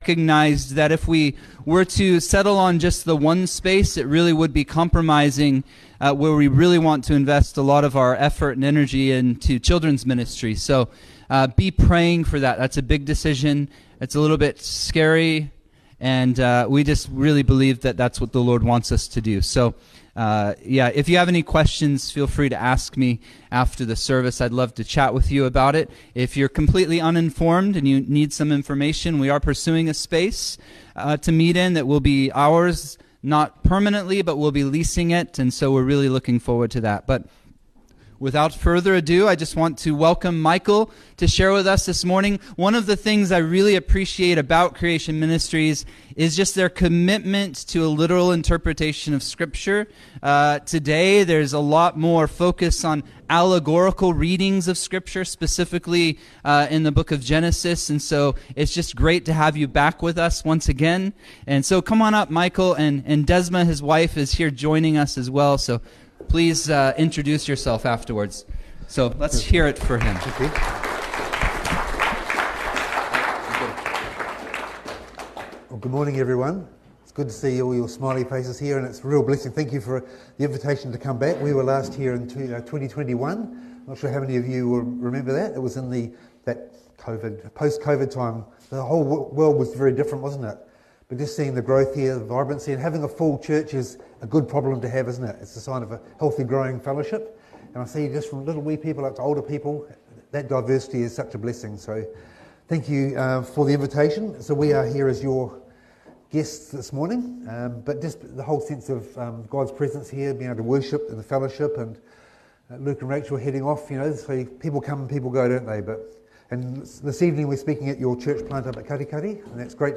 Sermons | Shoreline Calvary